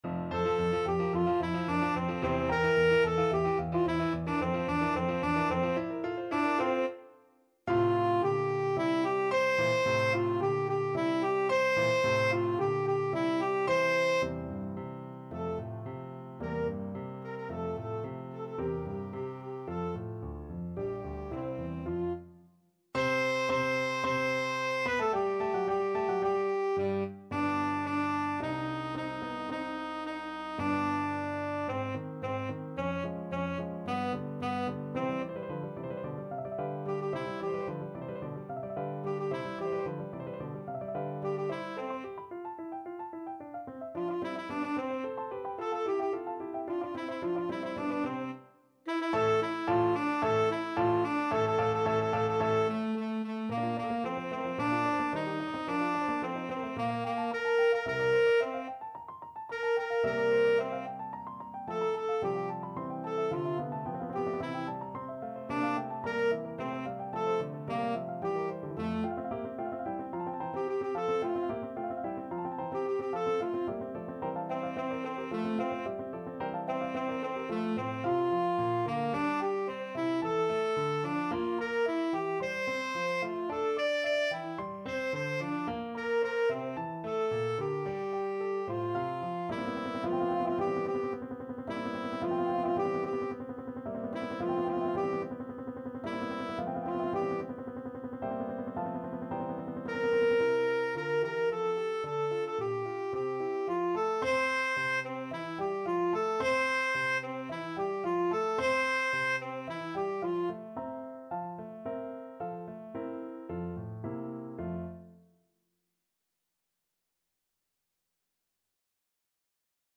Free Sheet music for Tenor Saxophone
Allegro assai =220 (View more music marked Allegro)
F major (Sounding Pitch) G major (Tenor Saxophone in Bb) (View more F major Music for Tenor Saxophone )
Tenor Saxophone  (View more Intermediate Tenor Saxophone Music)
Classical (View more Classical Tenor Saxophone Music)
mozart_don_ah_pieta_signori_TSAX.mp3